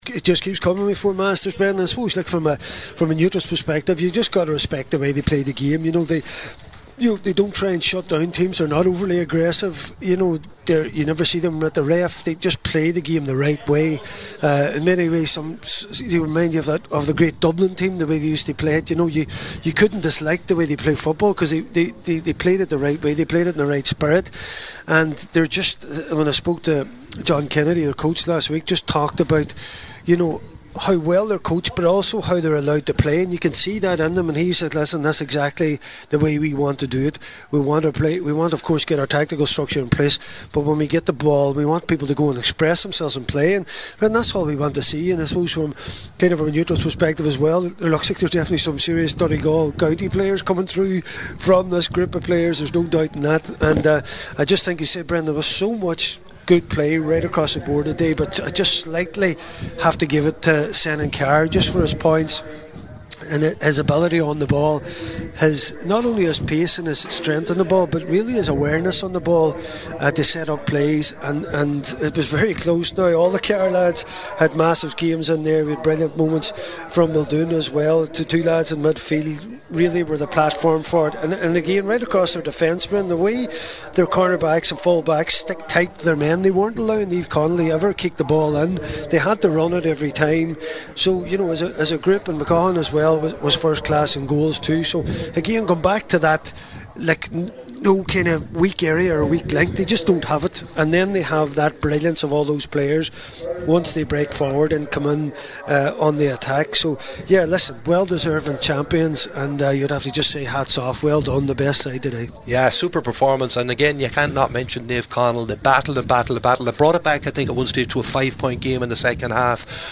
were live at full time for Highland Radio Sport…